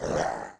Index of /App/sound/monster/orc_black
foot_act_1.wav